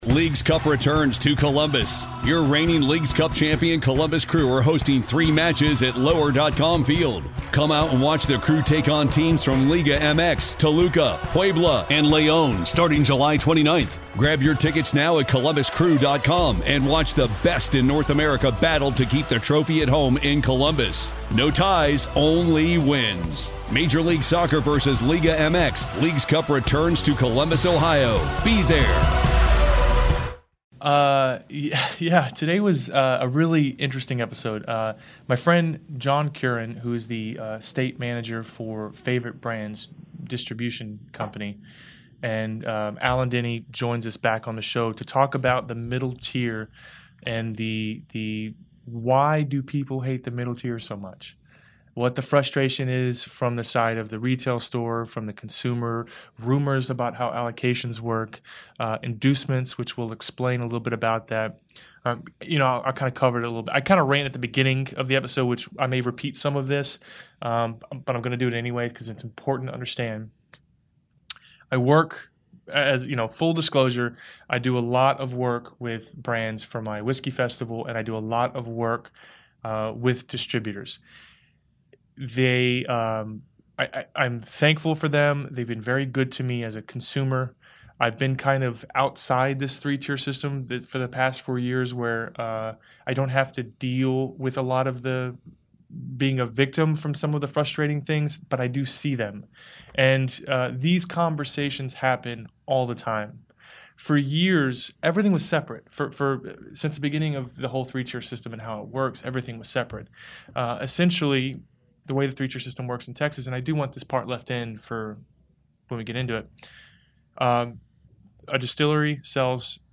We talk about the distributing tier and the laws broken, grey area work arounds and why they get painted as the bad guy. This was a really engaging episode for me, as it felt like one of the rawest and most honest conversations had on the subject.